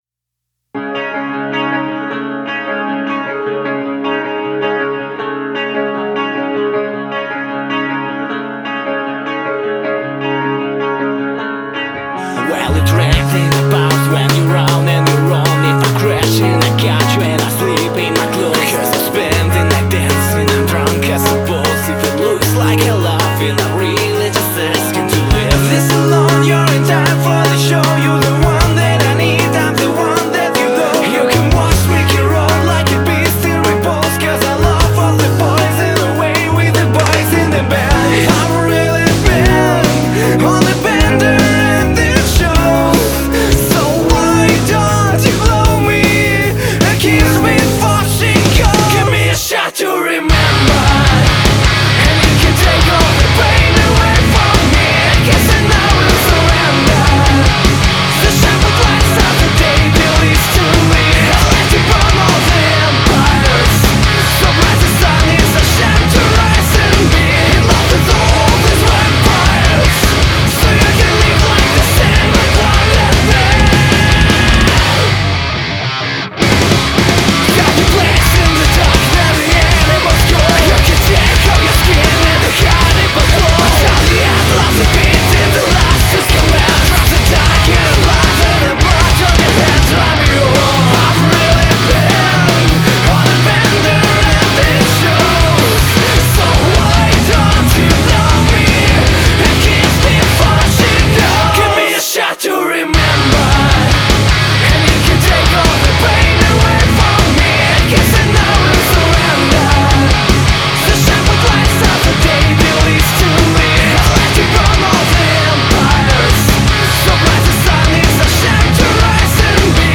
Кавер
Alt. Metal